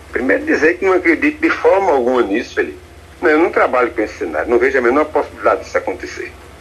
Em entrevista ao Arapuan Verdade, da Rádio Arapuan FM, desta segunda-feira (17/04), o deputado afirma não ver nenhuma possibilidade da aliança ser concretizada.
Cartaxo-sonora.m4a